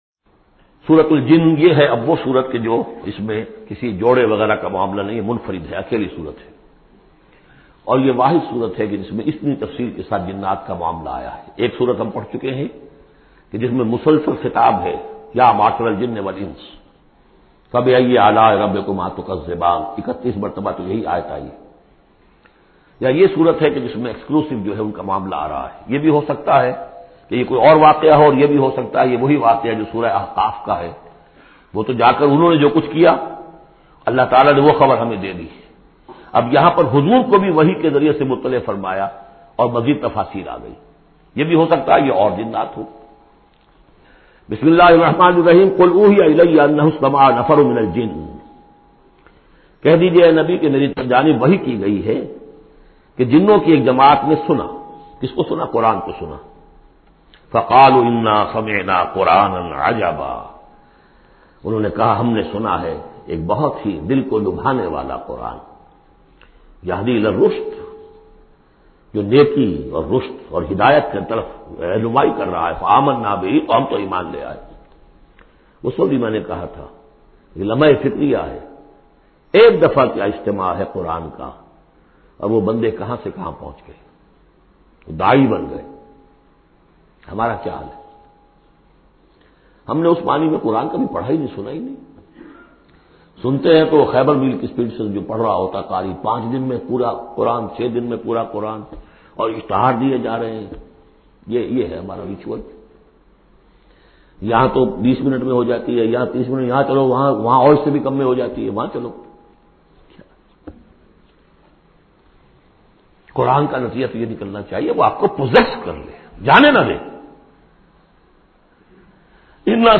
Surah Jinn is 72 chapter of Holy Quran. Listen online mp3 tafseer of Surah Jinn in the voice of Dr Israr Ahmed.